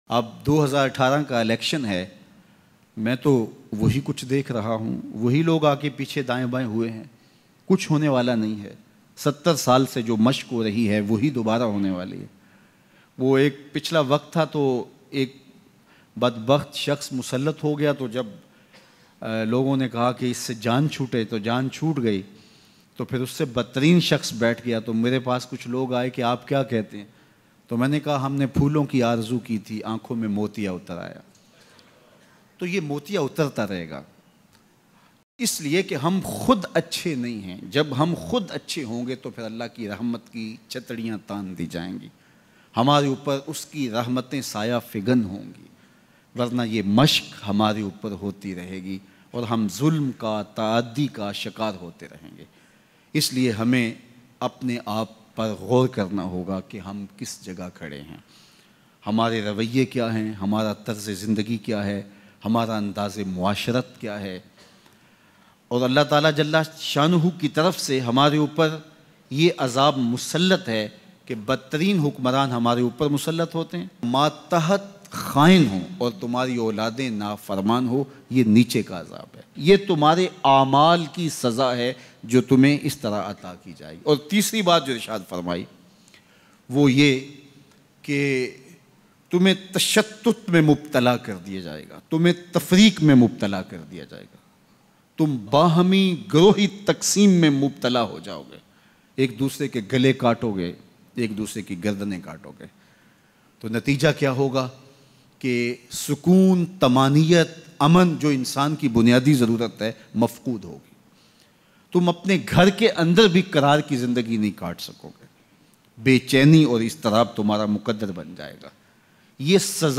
Election 2018 kis had tk umeed afza hai Bayan Mp3